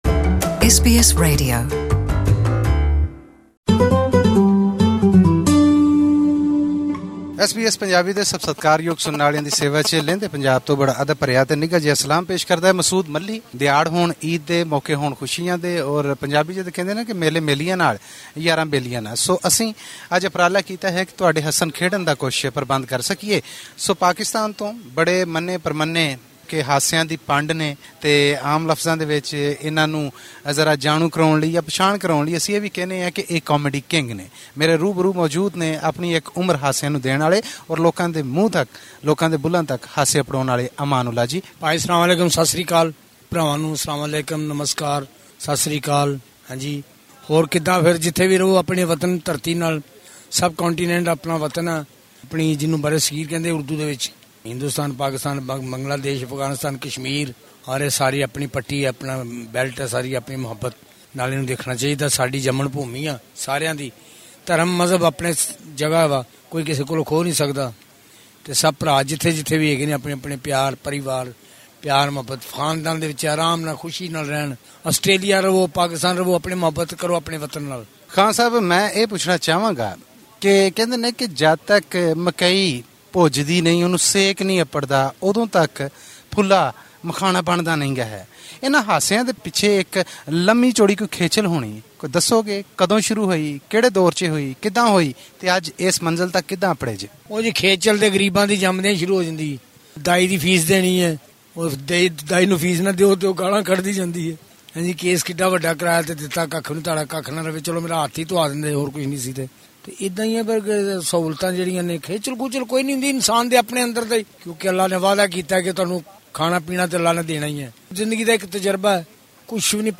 He attributes this partly to the lack of government support for theatre, but mostly to the trend of vulgar comedy on Pakistan’s stages. Listen to this interview in Punjabi.